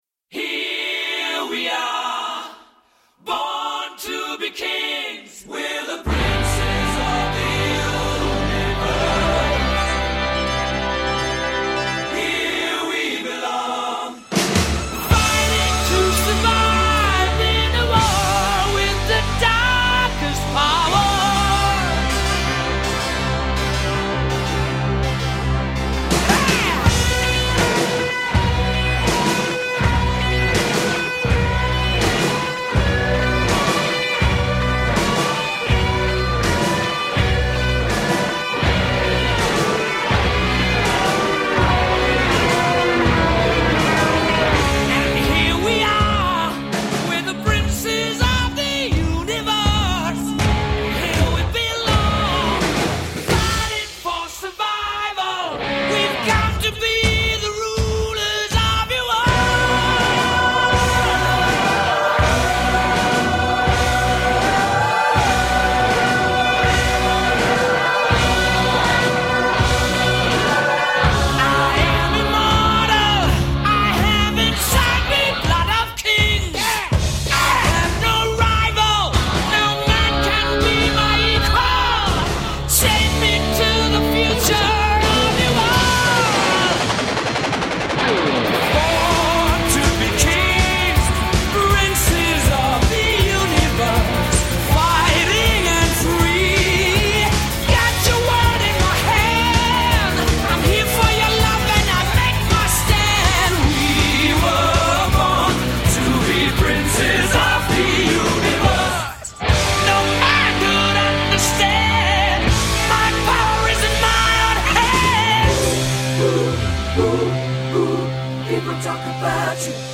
I’m also happy to say that the music is semi-themed this week!